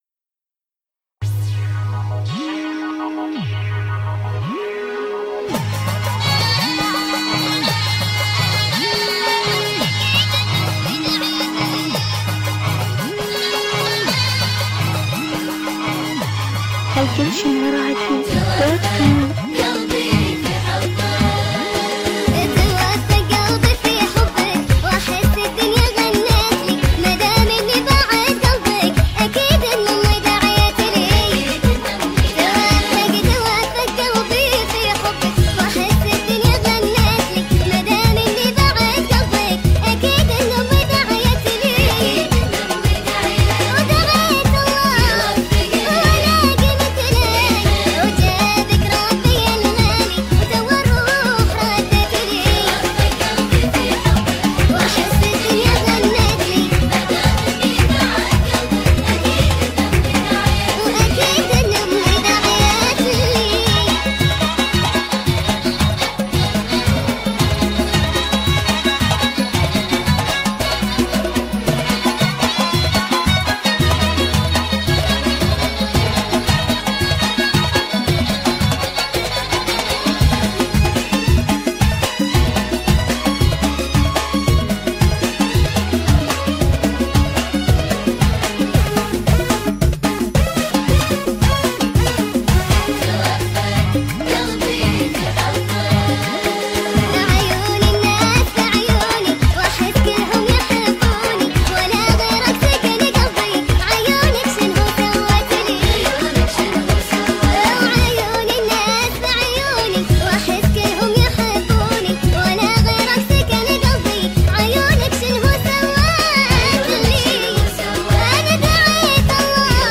اغاني هجولة خليجي
مسرع